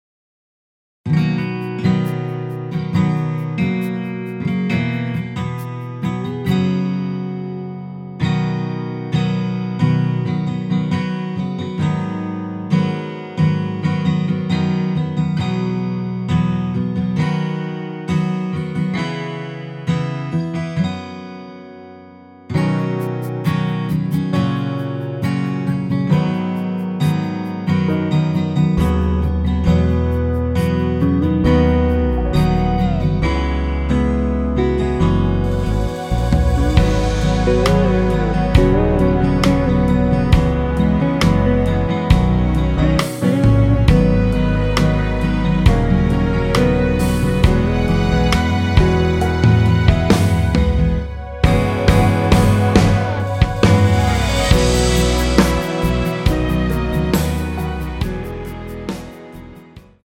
키 Db 가수